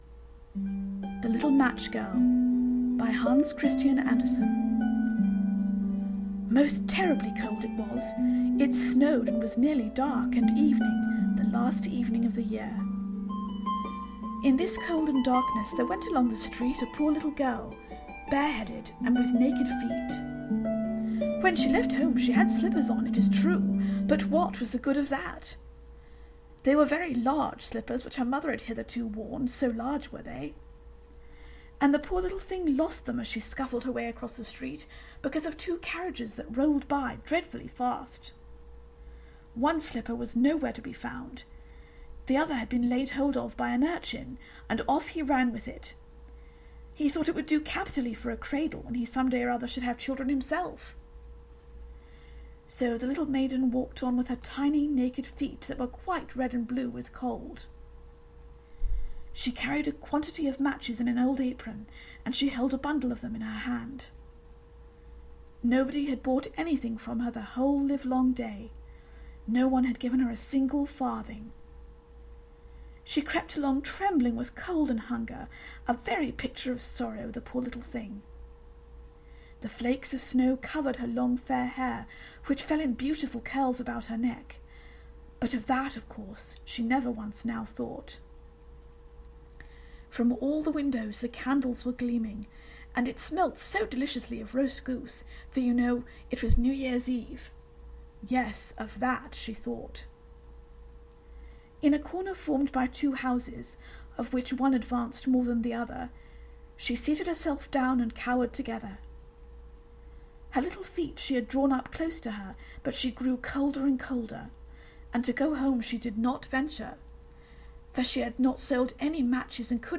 If you're wondering why I chose to narrate this fairy tale with an English accent, well, all I can say is that immitation is the sincerest form of flattery.
I therefore decided to narrate my own version in a similar vein. Besides, this is just one of those stories that needs a nice Oxford voice.